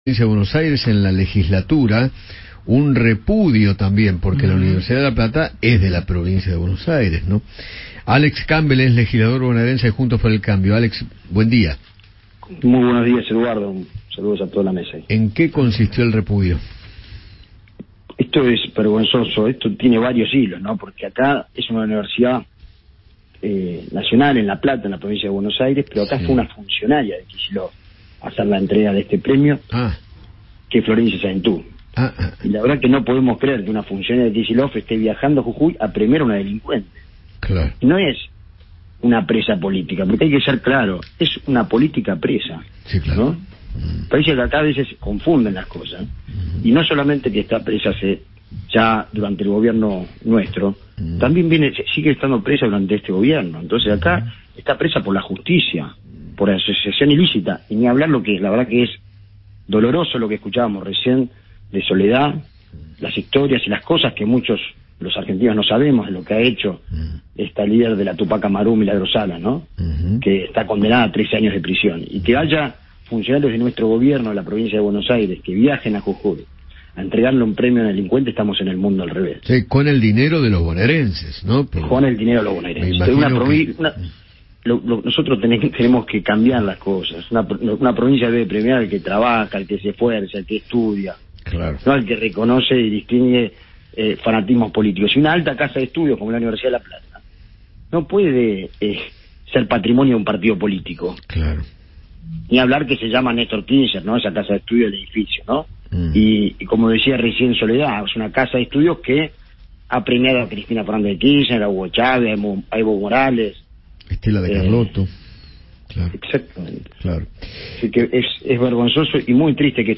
Alex Campbell, diputado por la Provincia de Buenos Aires, conversó con Eduardo Feinmann sobre la entrega del premio Rodolfo Walsh de la Universidad de La Plata a Milagro Sala y expresó que “estamos en el mundo del revés”.